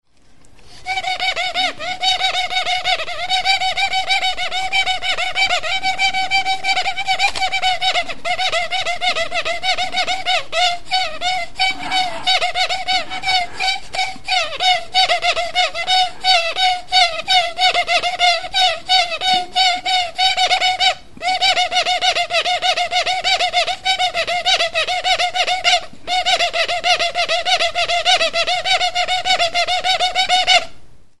Instruments de musiqueARTO BIOLINA
Cordes -> Frottées
Enregistré avec cet instrument de musique.
Bi arto zuztar zatiz egindako biolina da.